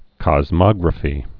(kŏz-mŏgrə-fē)